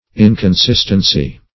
Inconsistency \In`con*sist"en*cy\, n.; pl. Inconsistencies.